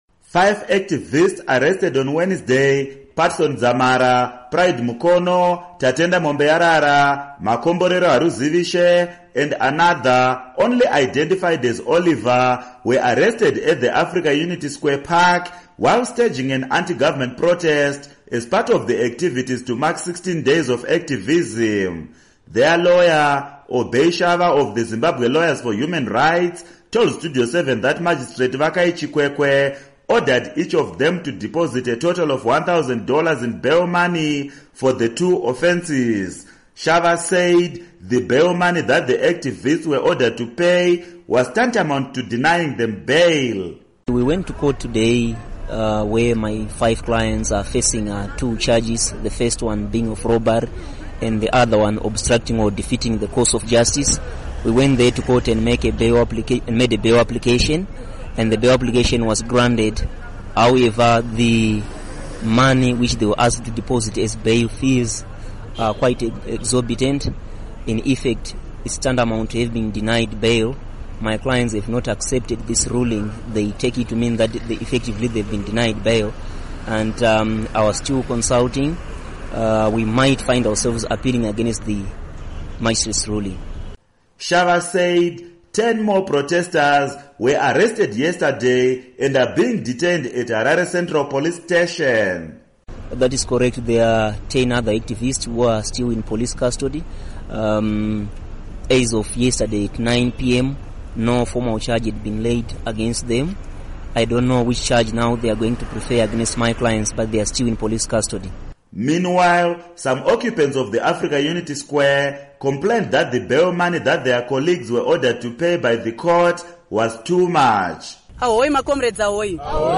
Report on Africa Unity Square